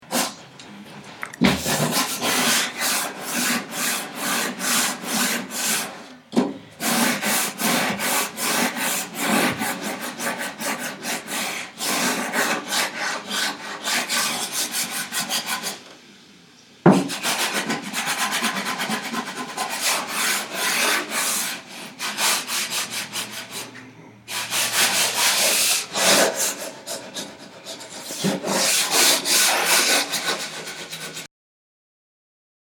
Field Recording
My dog Buddy’s very soft breathing and rare haruffing.